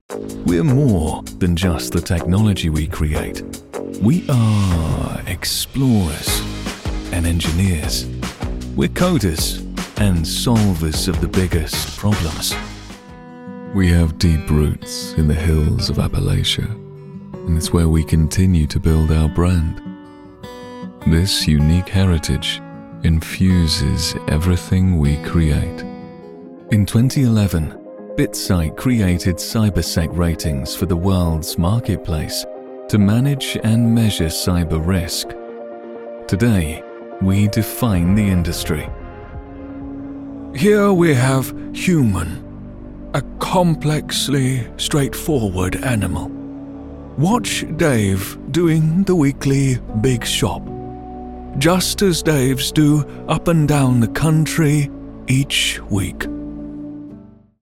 Male
English (British)
His voice puts the listener at ease, while keeping a confidence that doesn't patronise.
Corporate
Words that describe my voice are warm, down-to-earth, relatable.
All our voice actors have professional broadcast quality recording studios.